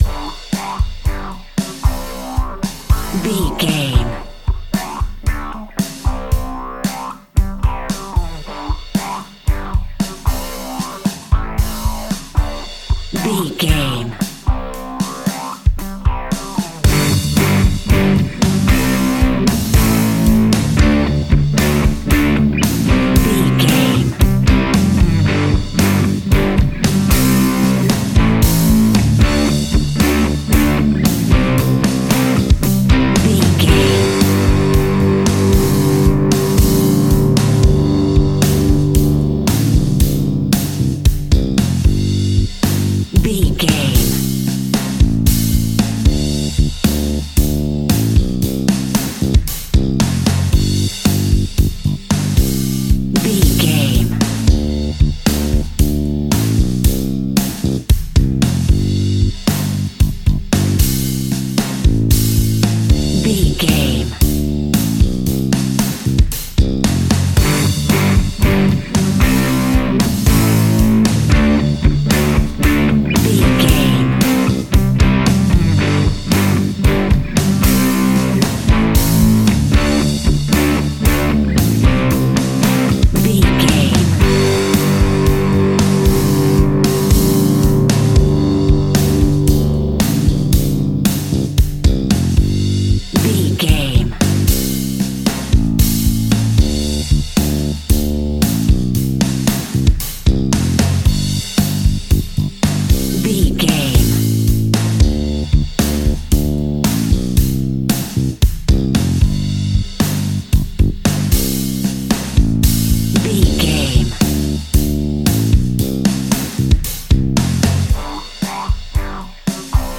Epic / Action
Fast paced
Ionian/Major
hard rock
blues rock
distortion
instrumentals
rock guitars
Rock Bass
Rock Drums
heavy drums
distorted guitars
hammond organ